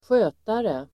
Uttal: [²sj'ö:tare]